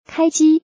power_on.mp3